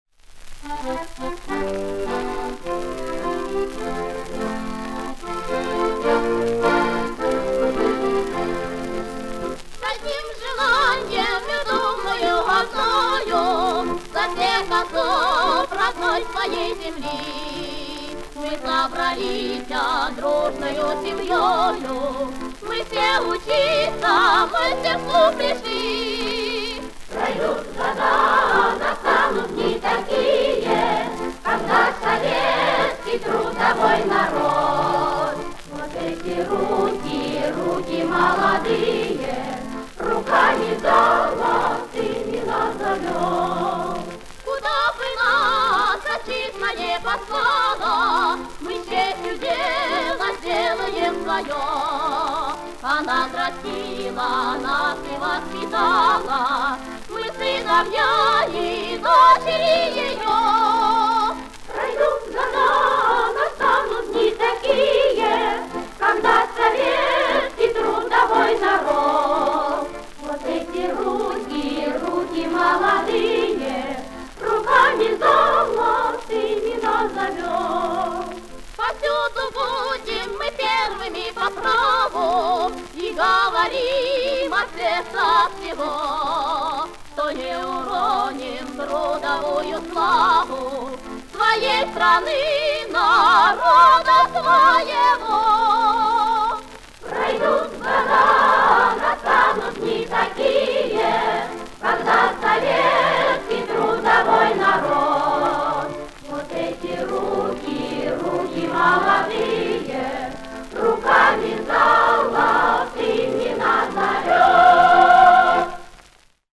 трио баянистов